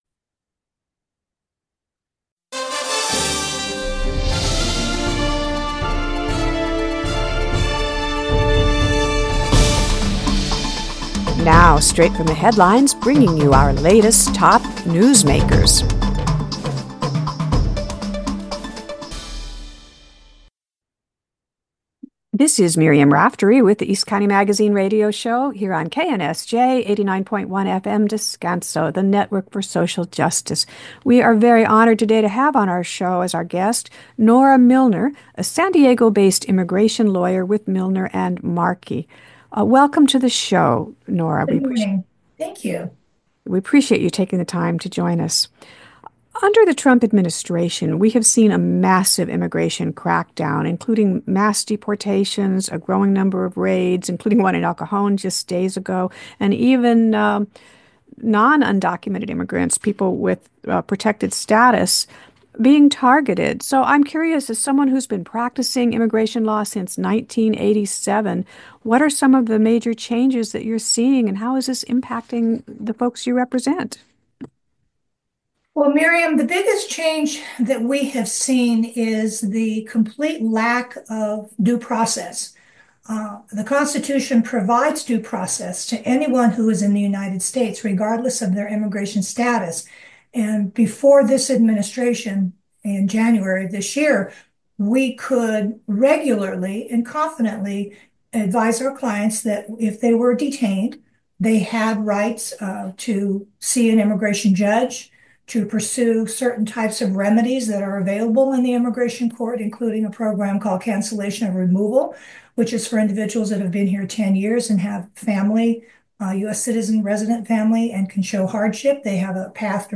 East County Magazine Live! Radio Show